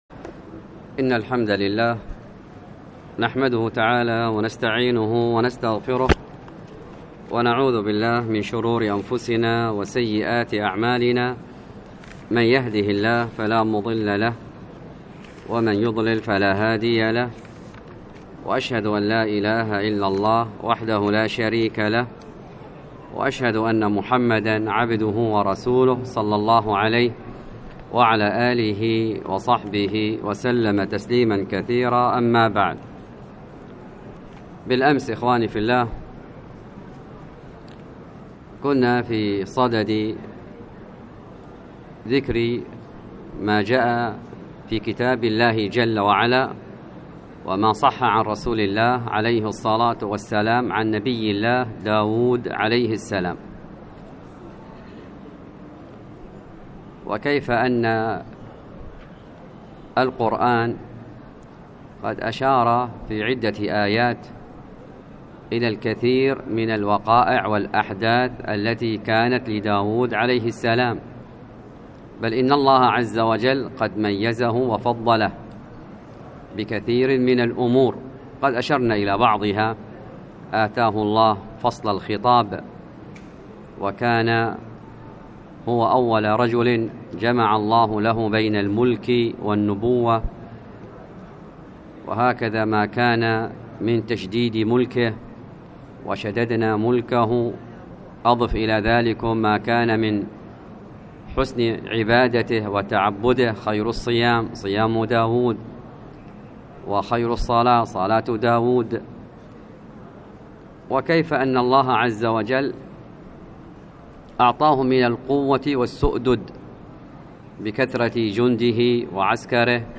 المختصر في تاريخ الأنبياء والرسل | الدروس